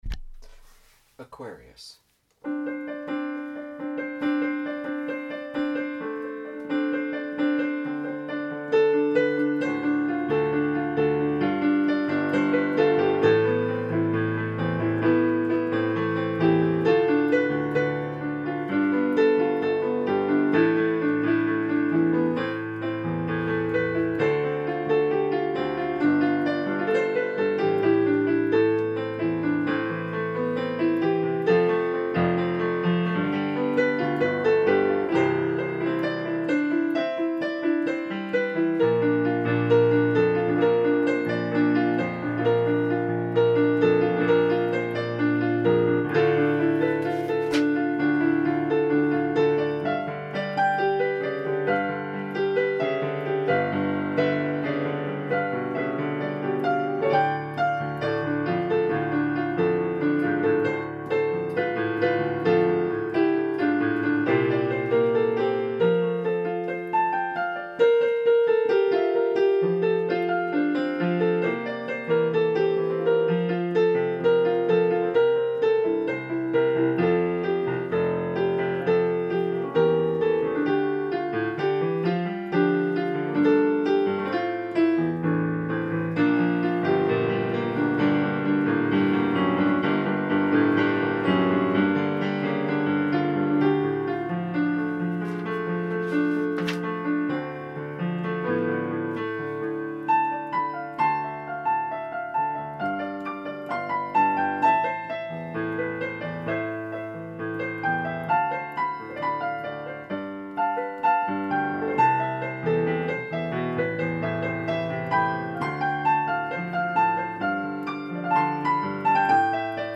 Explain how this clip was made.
Memorial Celebration Program